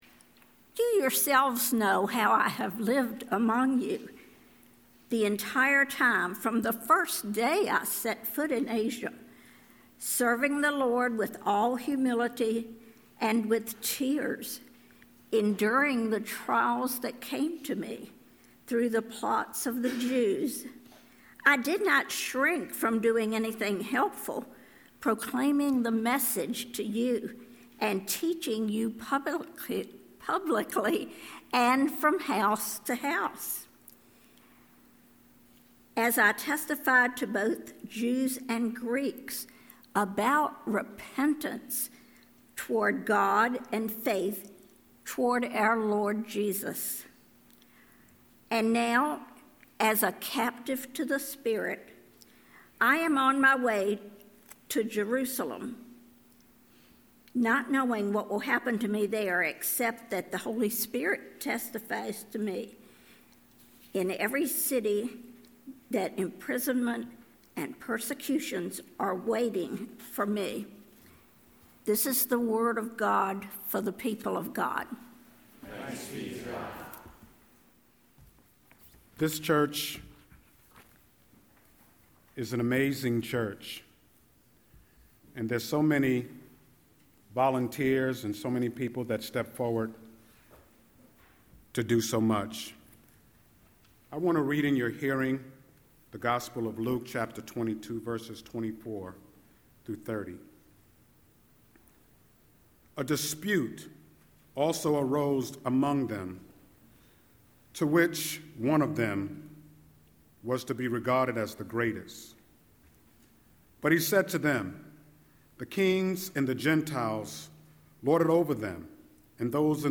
Listen to this week’s Scripture and Sermon
10-04-Scripture-and-Sermon.mp3